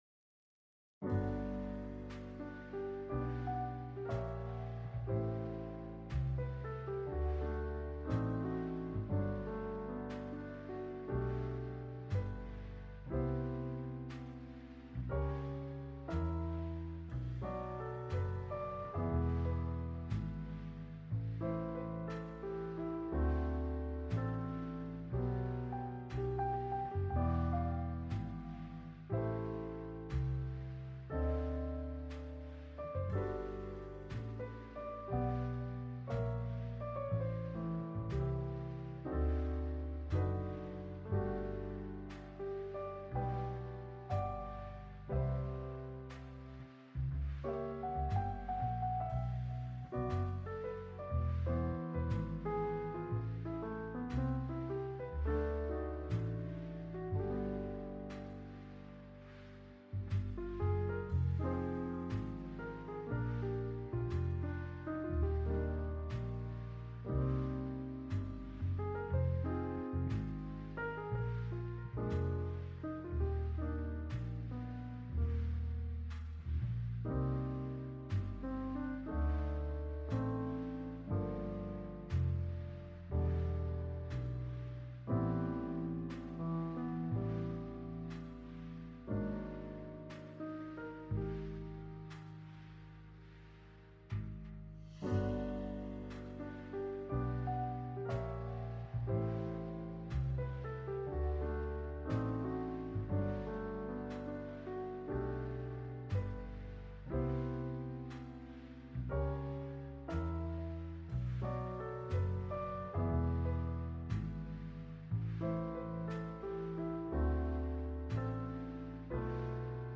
Timer Eau : Immersion Focus